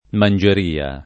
mangeria [ man J er & a ] s. f.